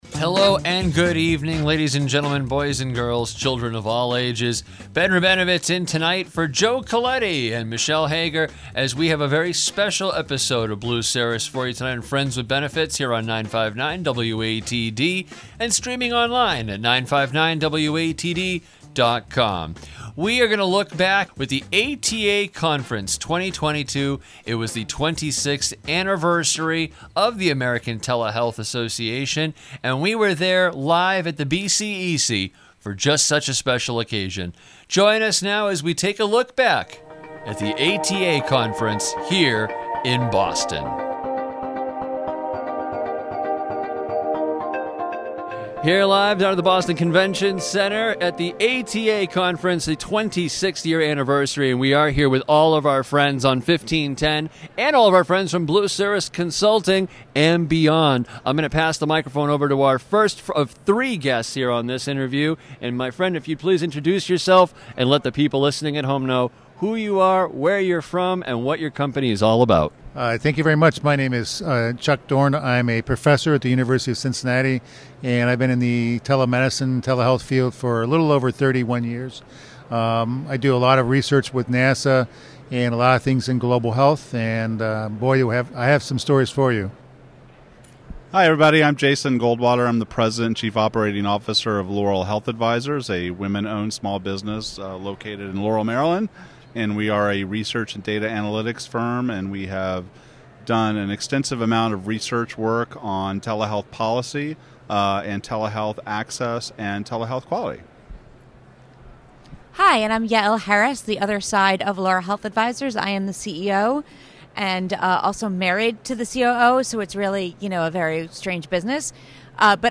Check out the recordings from our Blue Cirrus Consulting booth at the 2022 American Telemedicine Association (ATA) conference!